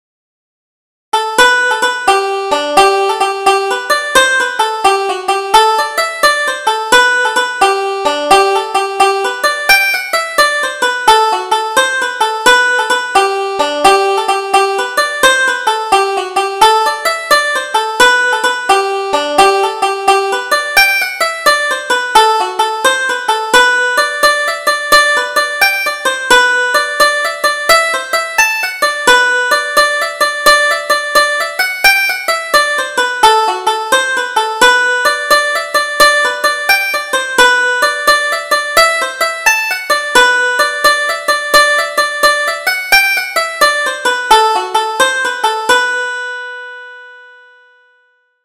Double Jig: The End of the Day